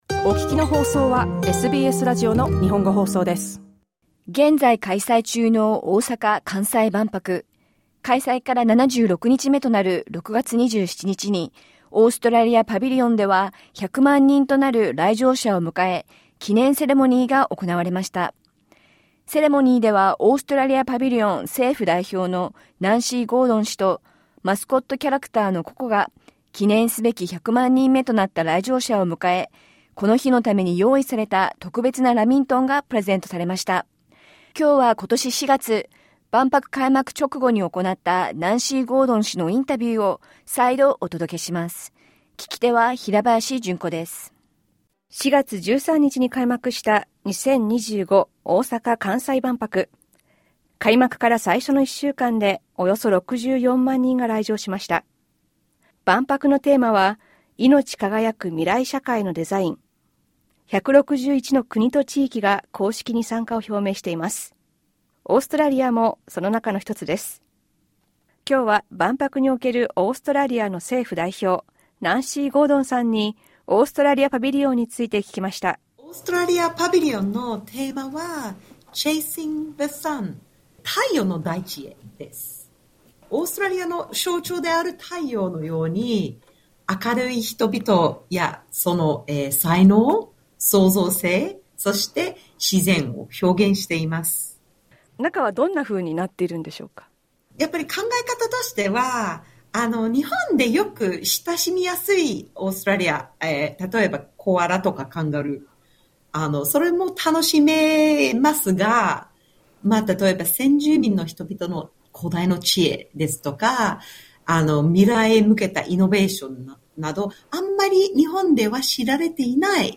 今週のインタビュー